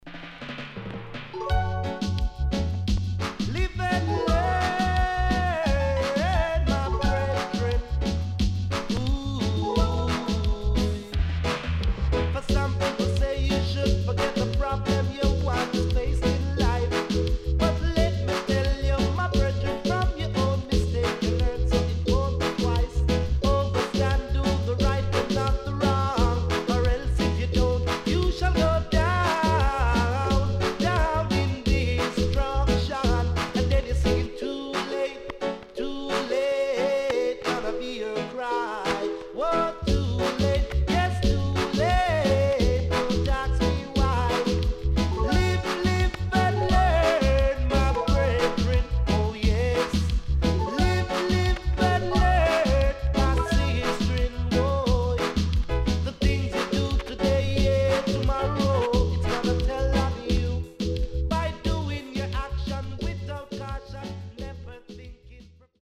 HOME > REISSUE USED [DANCEHALL]
SIDE A:少しノイズ入りますが良好です。